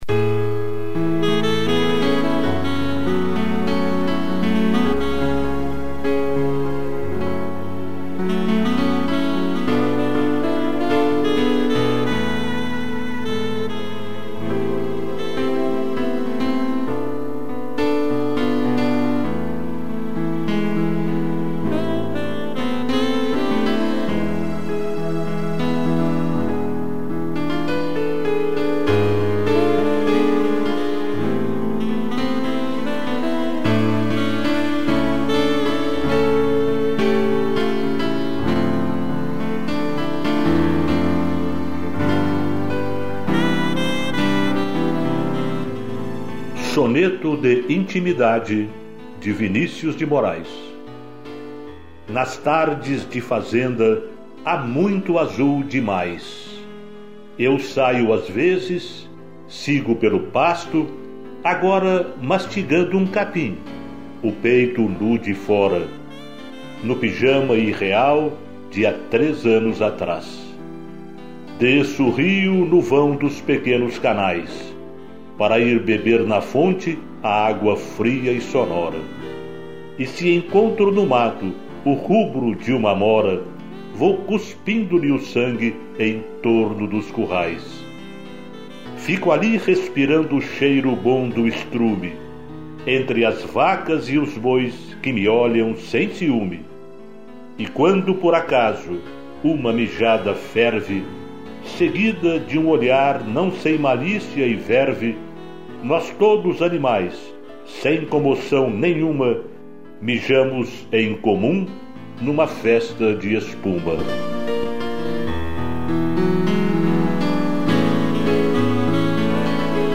piano, sax e strings